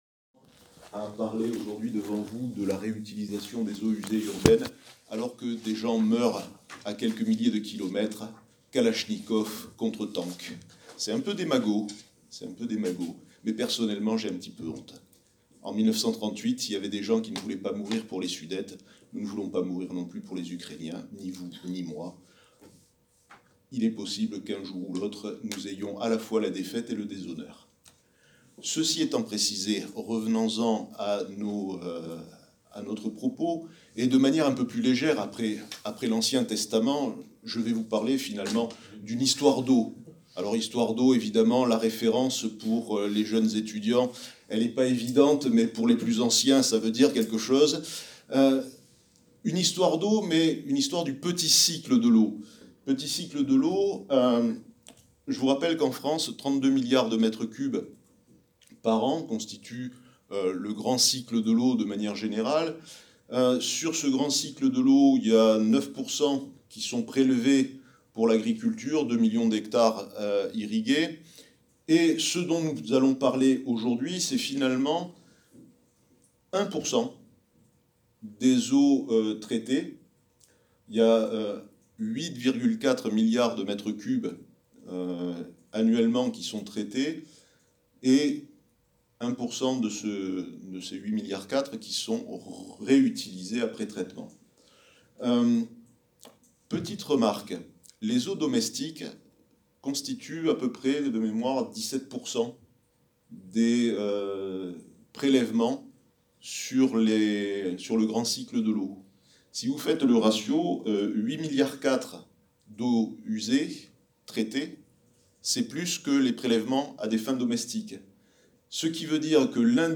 Réécouter la conférence et les questions en podcast